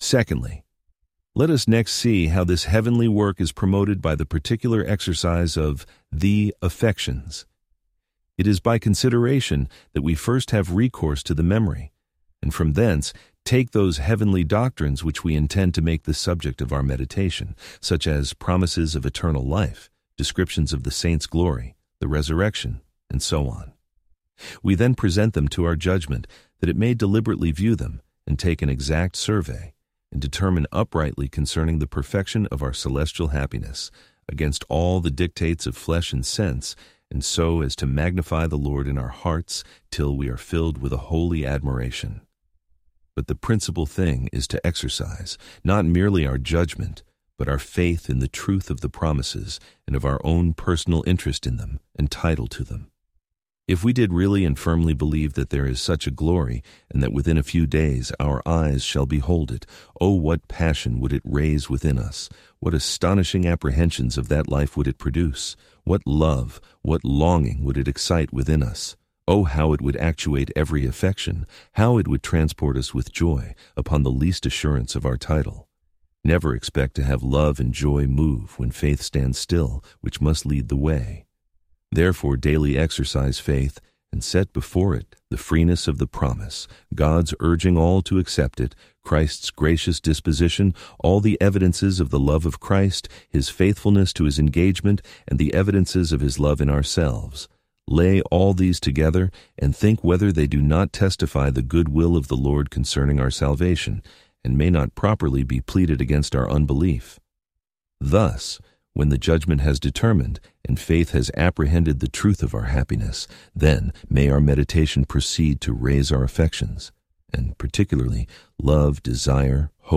Complete Audiobook Play Download Individual Sections Section 1 Play Download Section 2 Play Download Listening Tips Download the MP3 files and play them using the default audio player on your phone or computer.